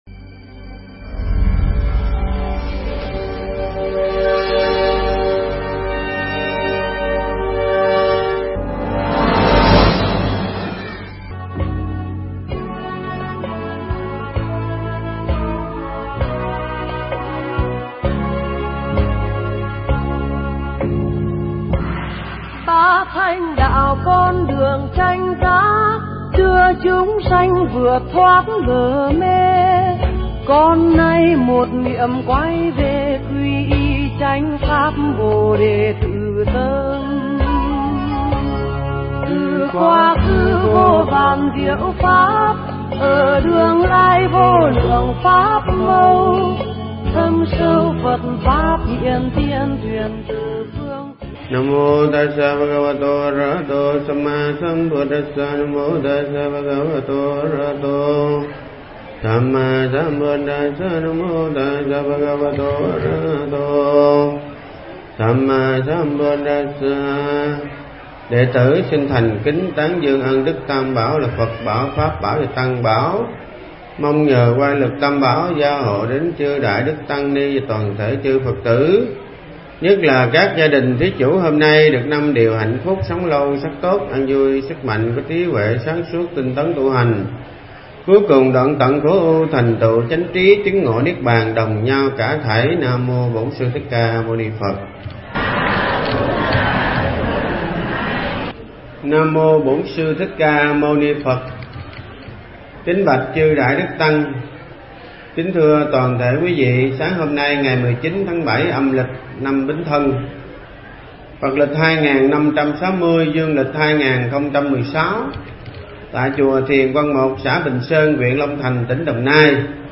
Nghe Mp3 thuyết pháp Tứ Trọng Ân
Mp3 pháp thoại Tứ Trọng Ân